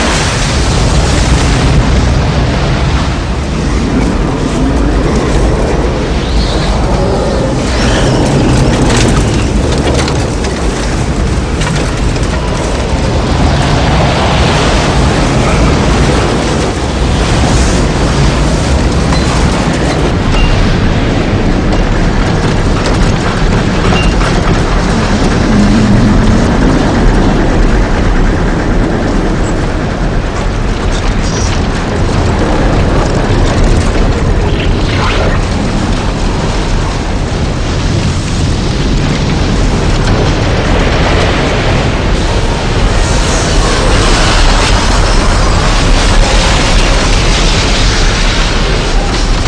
tornado.wav